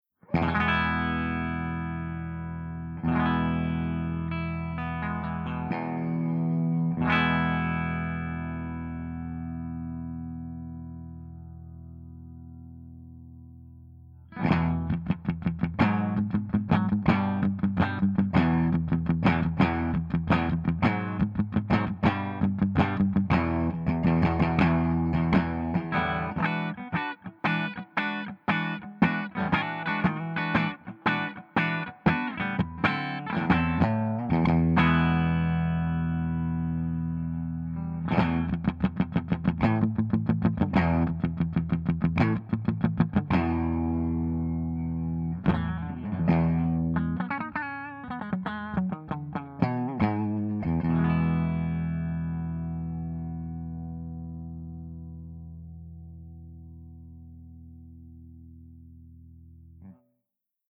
069_FENDERSUPERREVERB_BRIGHTREVERB_HB.mp3